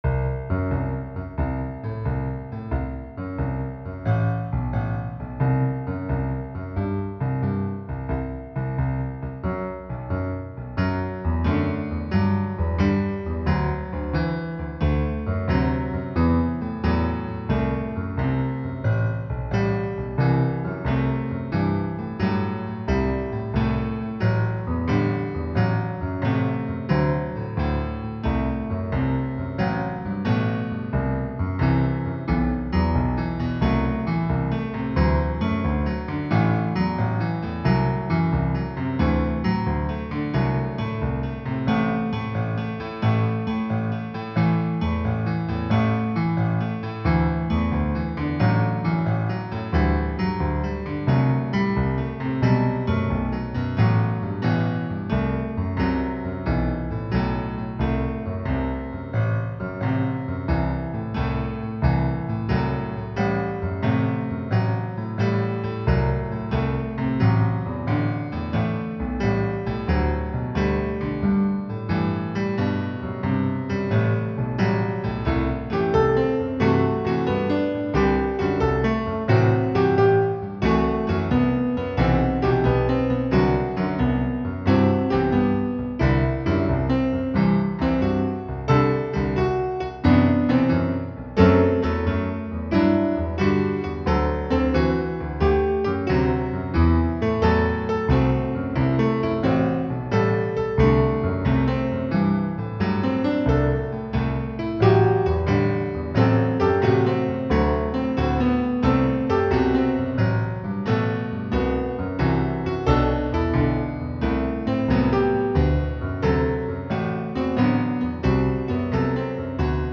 Hopalong (0.91 ) Do (C) Penta 126